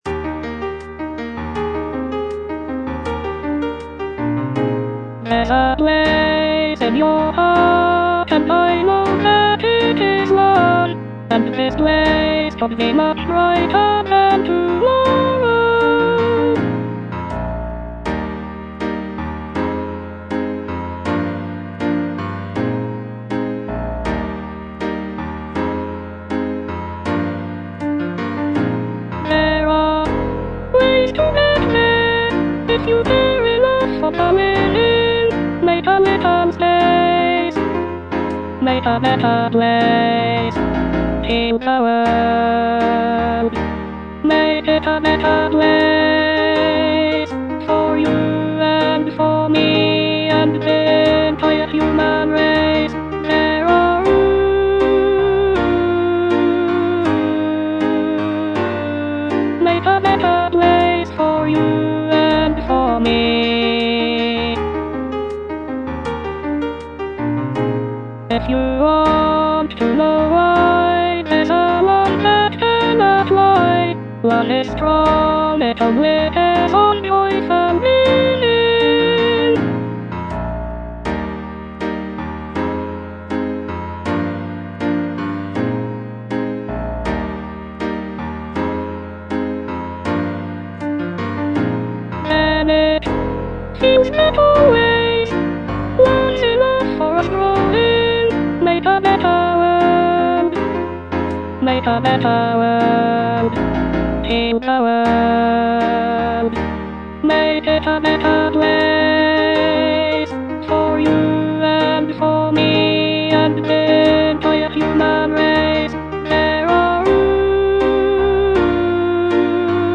Alto I, small group (Voice with metronome)